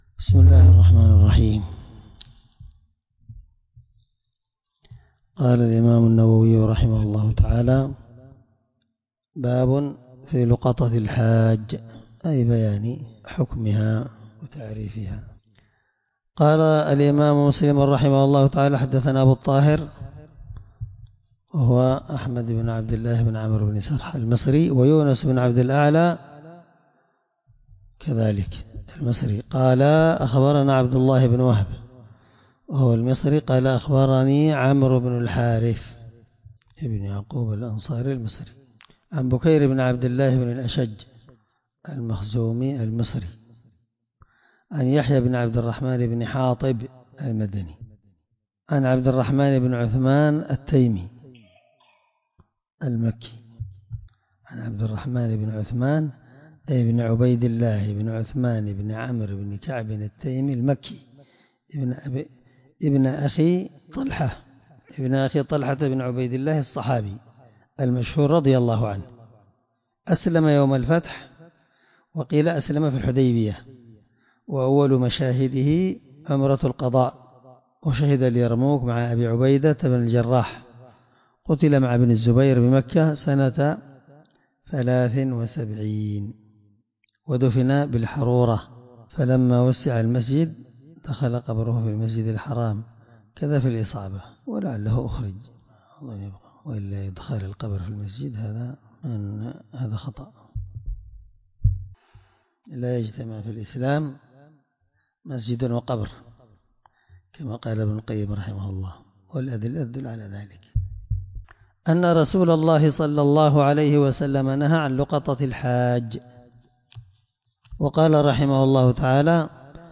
الدرس3من شرح كتاب اللقطة الحدود حديث رقم(1724-1725) من صحيح مسلم